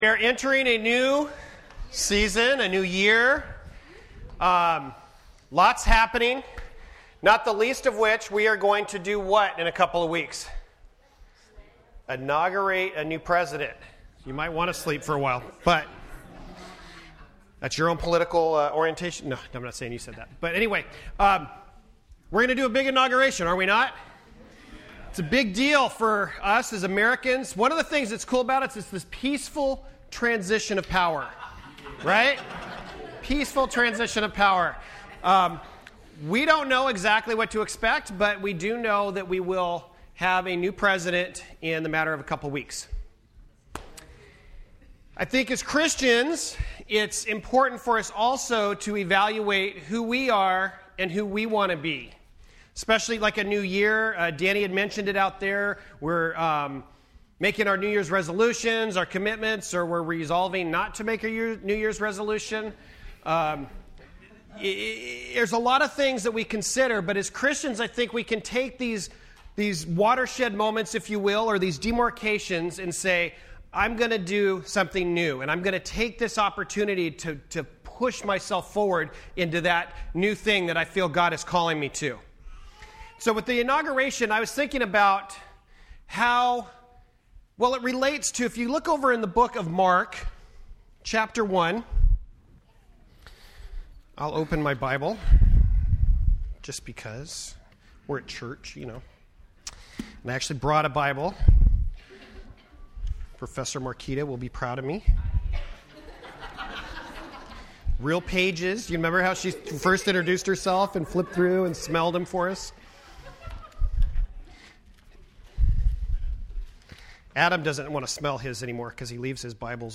Series: FWC Sermons Service Type: Sunday Morning %todo_render% Related « New Year’s Day